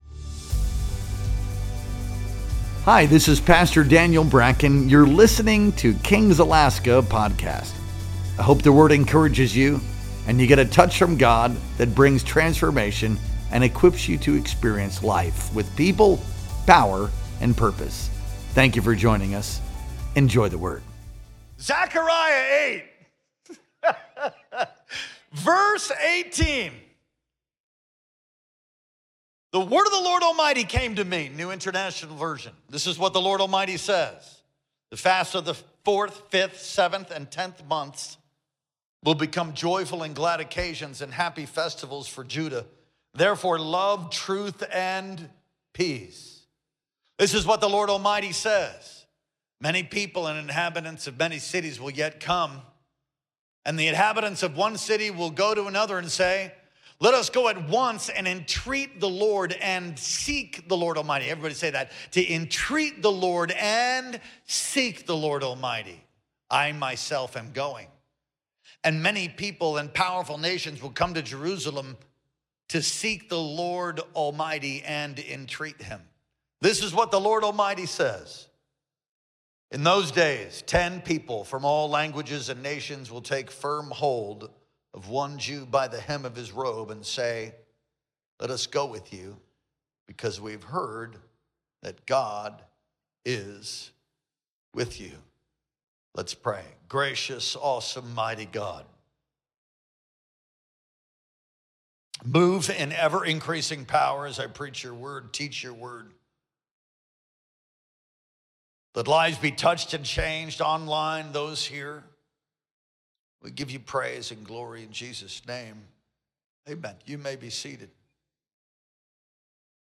Our Wednesday Night Worship Experience streamed live on May 21st, 2025.